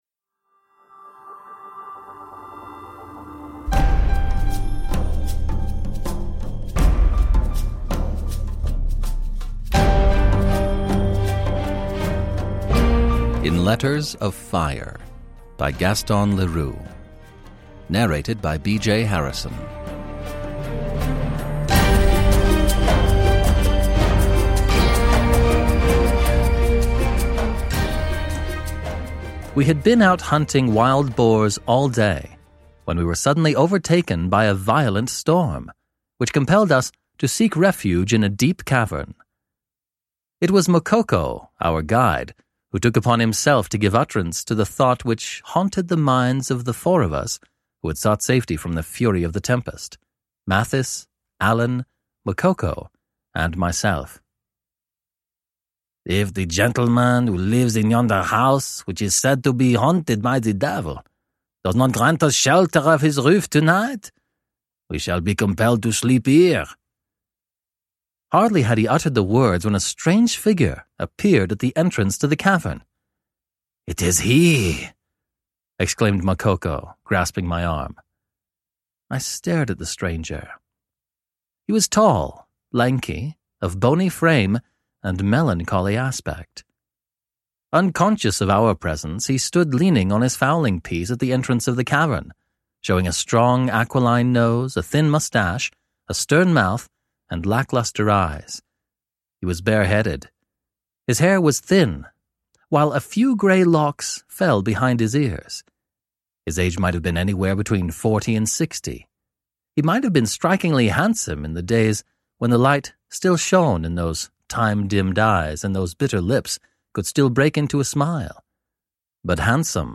He masterfully plays with a wide array of voices and accents and has since then produced over 500 audiobooks.